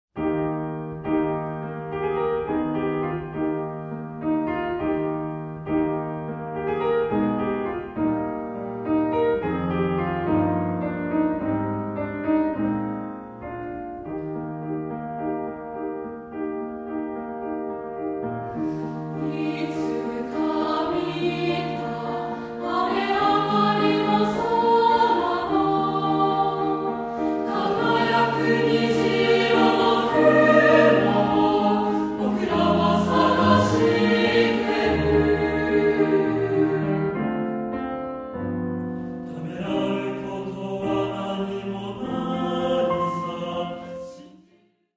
混声3部合唱／伴奏：ピアノ